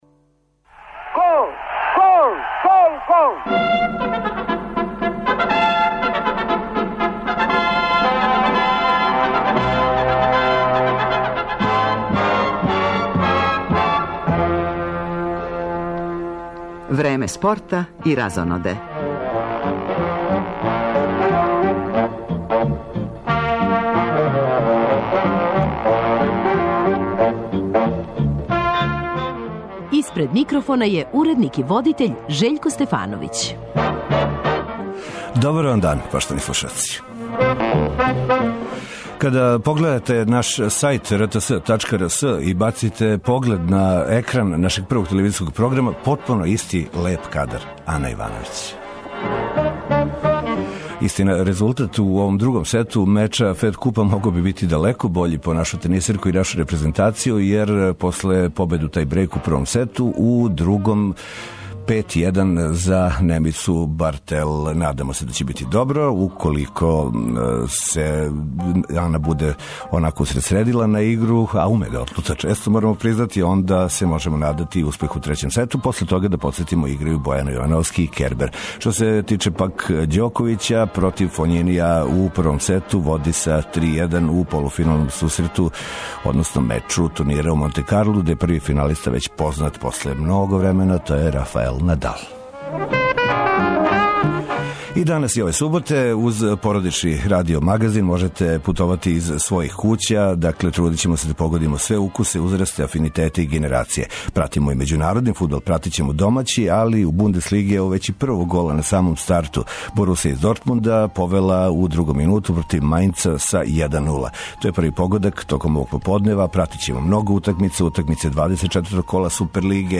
Током поподнева пратимо званични тренинг возача Формуле 1 уочи такмичења за "Велику награду Бахреина", фудбалске утакмице Супер и Прве лиге Србије, укључујући јављање репортера са утакмице ОФК Београд-Нови Пазар.